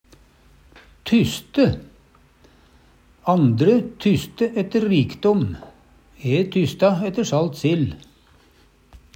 tyste - Numedalsmål (en-US)